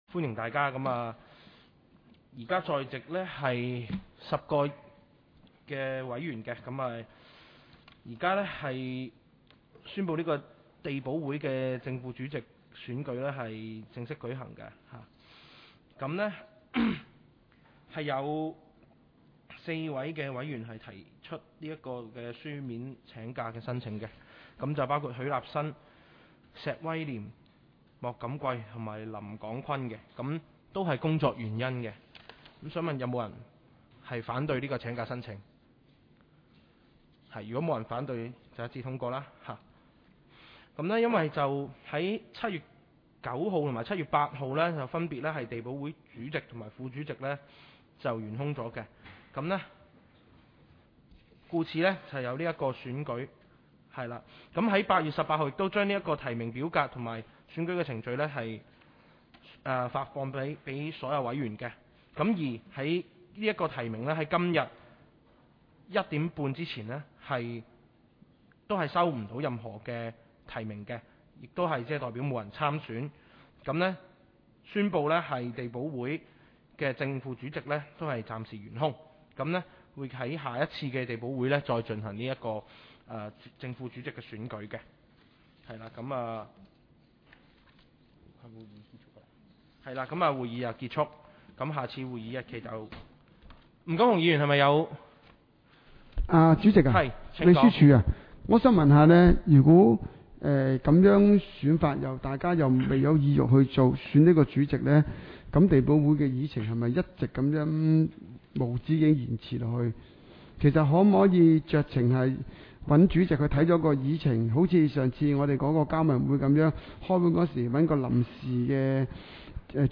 委员会会议的录音记录
地区设施管理及保安事务委员会第三次会议 日期: 2021-08-26 (星期四) 时间: 下午2时30分 地点: 沙田民政事务处 441 会议室 议程 讨论时间 I 选举委员会主席及副主席 00:08:31 全部展开 全部收回 议程:I 选举委员会主席及副主席 讨论时间: 00:08:31 前一页 返回页首 如欲参阅以上文件所载档案较大的附件或受版权保护的附件，请向 区议会秘书处 或有关版权持有人（按情况）查询。